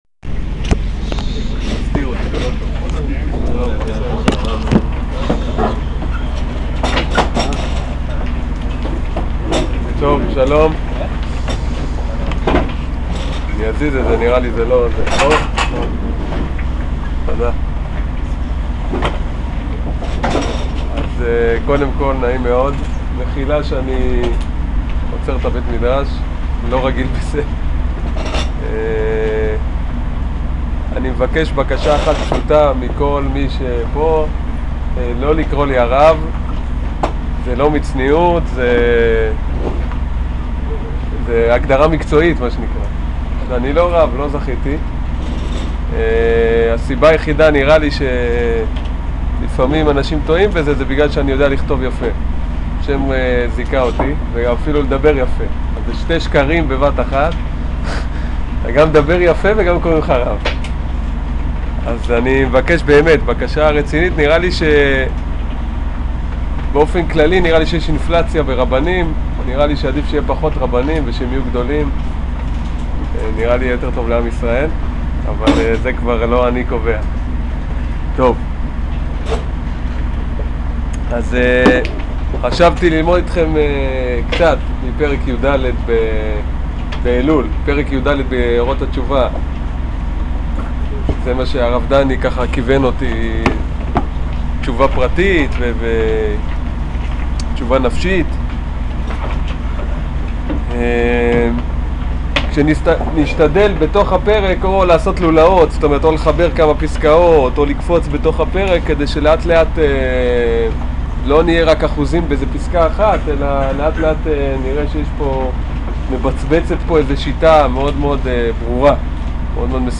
שיעור פרק יד פסקה א